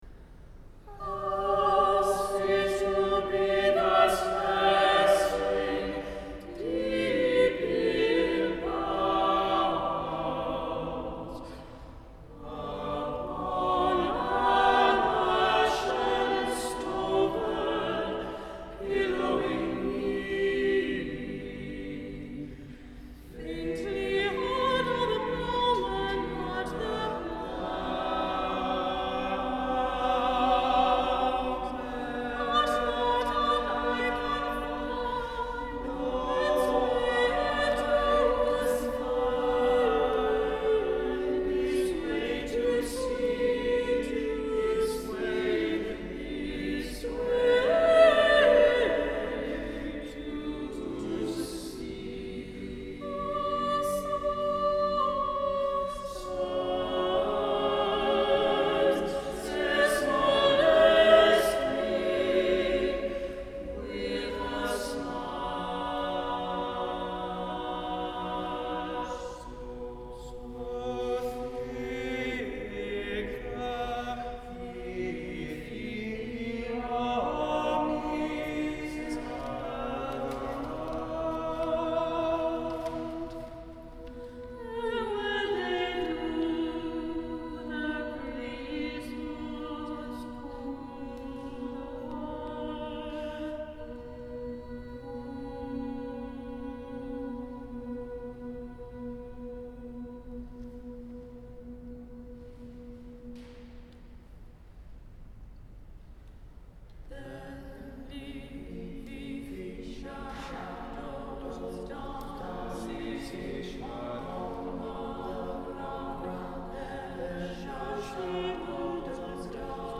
In Hilly Wood SSAATTBB piece performed by the Queensgate Singers in September 2015.